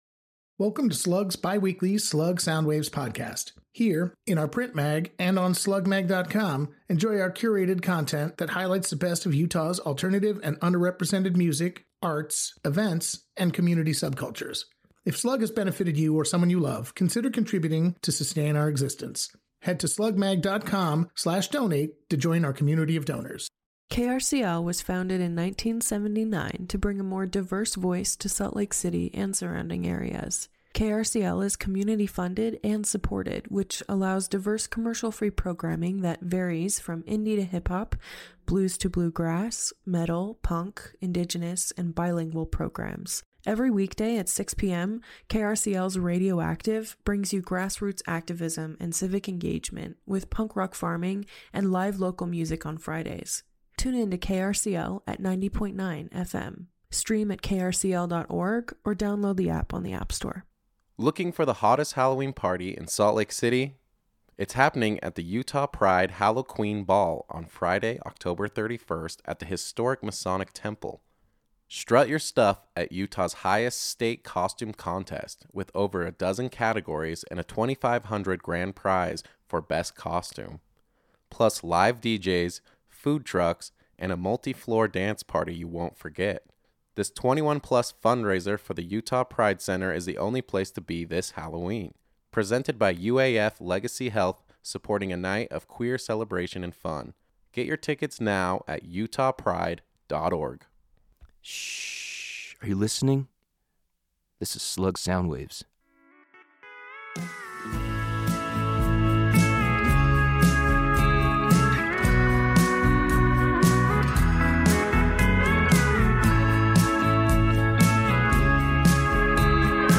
folky Americana